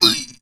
PlayerHurt2.wav